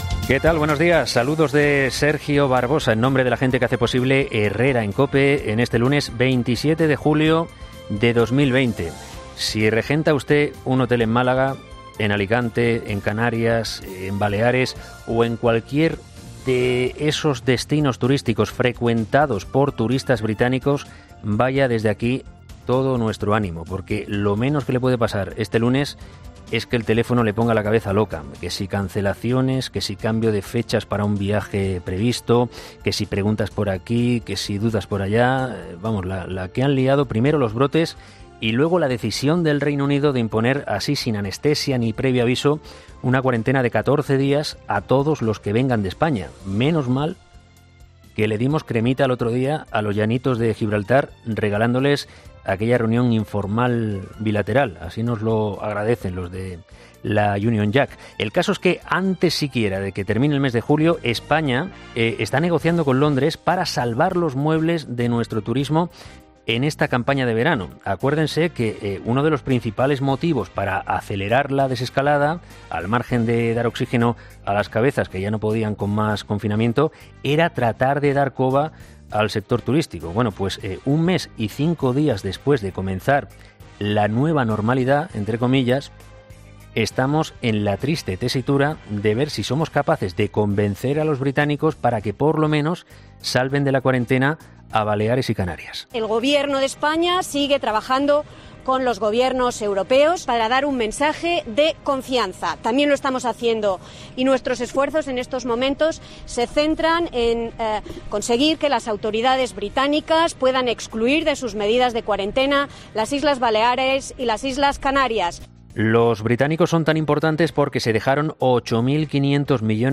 ESCUCHA AQUÍ EL EDITORIAL